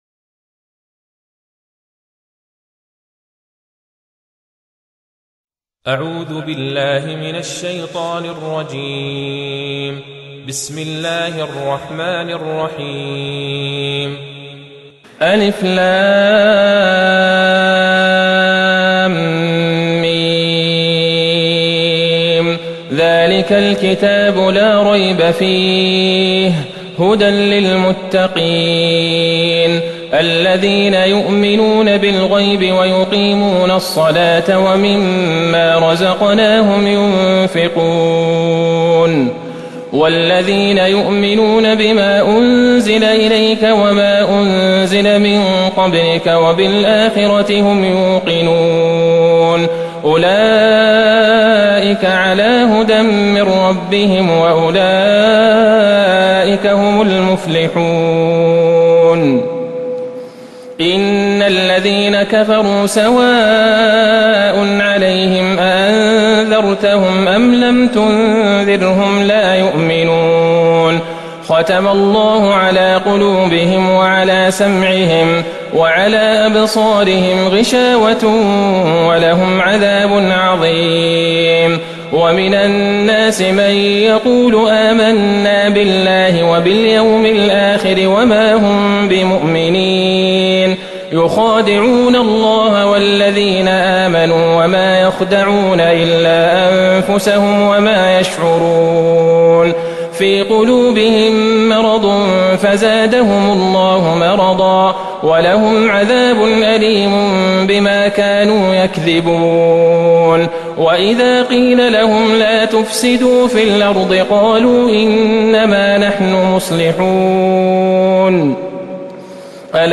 سورة البقرة Surat Al-Baqara > مصحف الشيخ عبدالله البعيجان من الحرم النبوي > المصحف - تلاوات الحرمين